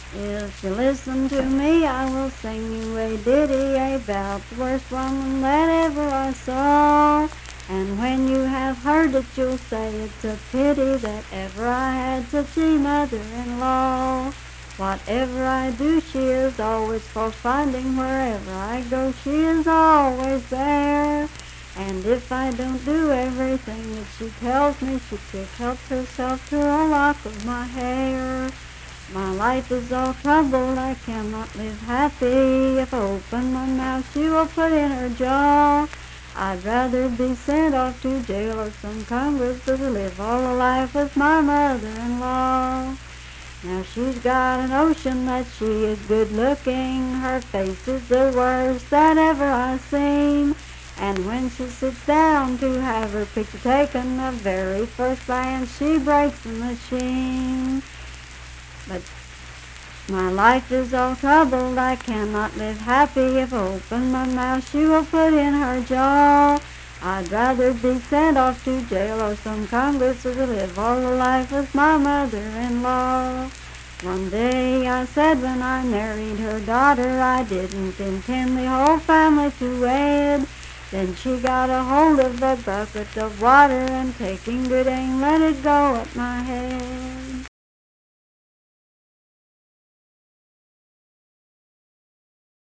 Unaccompanied vocal music
Verse-refrain 6(4w/R).
Voice (sung)
Braxton County (W. Va.), Sutton (W. Va.)